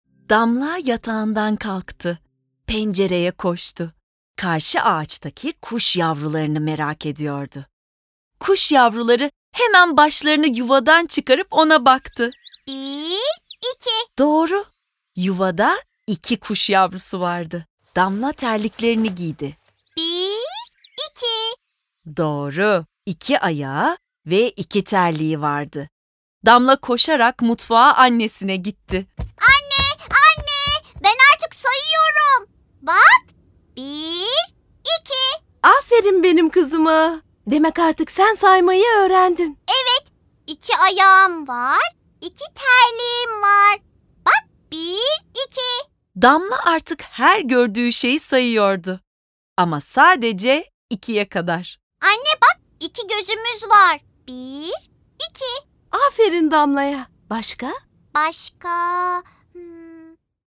Damla Beşe Kadar Sayıyor sesli tiyatrosu ile eğlenceli ve eğitici bir maceraya çıkın.